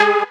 Mainlead_Melody23.ogg